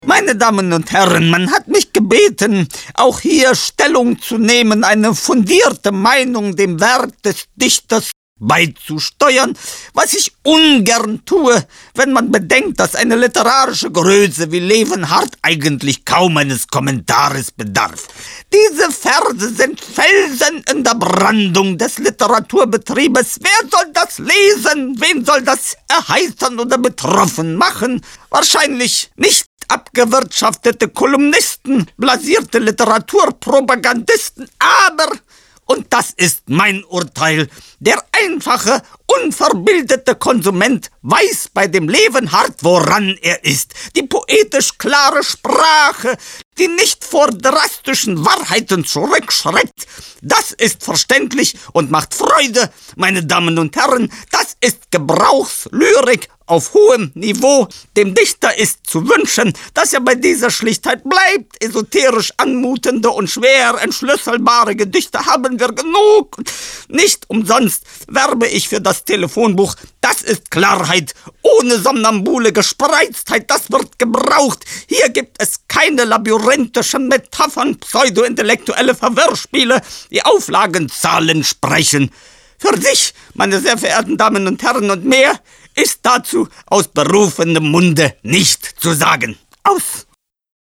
Geschichten, Kabarettszenen, Parodien und Minihörspiele.